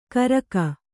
♪ karaka